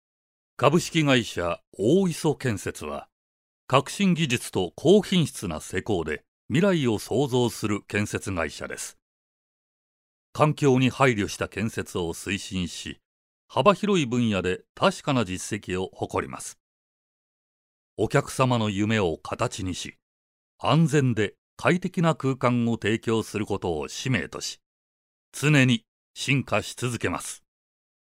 落ち着いた／穏やか
知的／クール
アルト／重厚感
どことなく和を感じさせる低音。
ボイスサンプル1（会社案内） [↓DOWNLOAD]
正確に内容を伝える技術に加え、落ち着いた声質で作品の雰囲気づくりをお手伝いいたします。